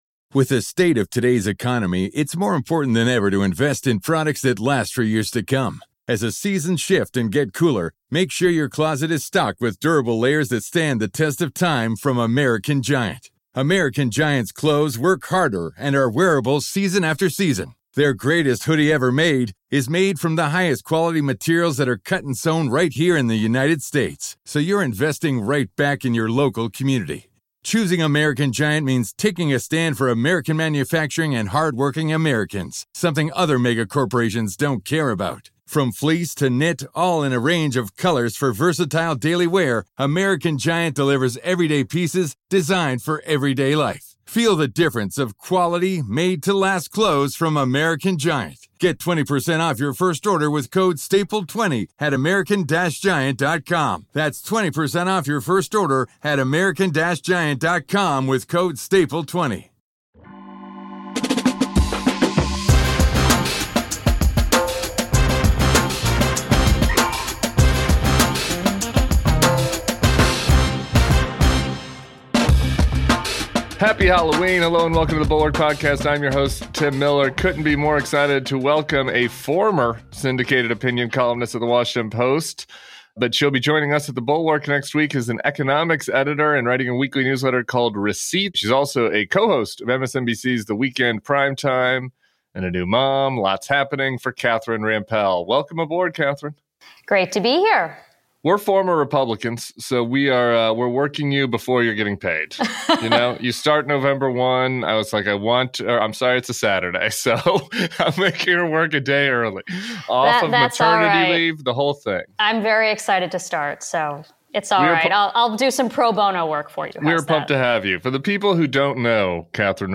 The Bulwark’s Catherine Rampell joins Tim Miller for the Halloween weekend pod.